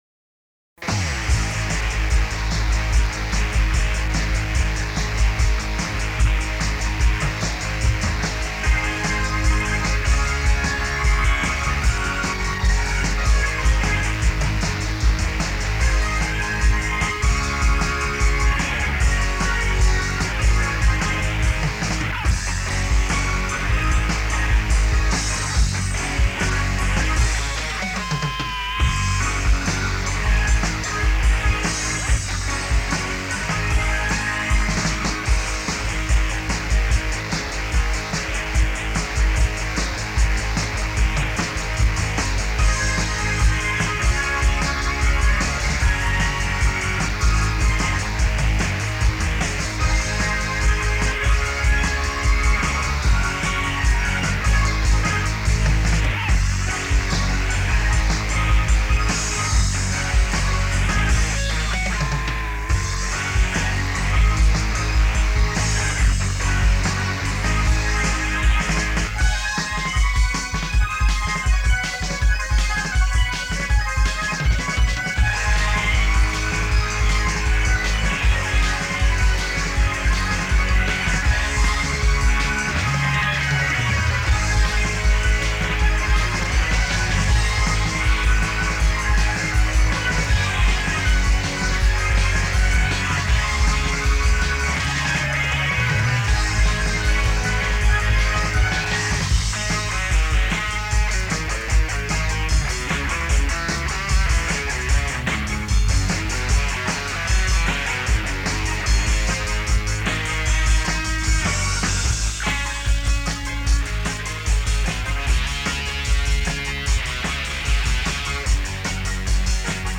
Guitar
Keyboards
Bass Guitar
Drums